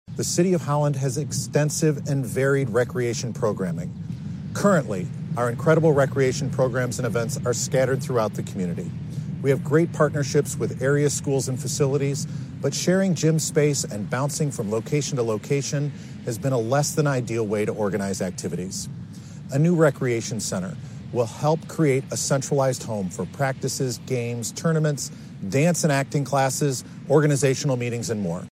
Mayor Nathan Bocks explained the need in a promotional video released by the city two and a half years ago.
holland-1120-bocks.mp3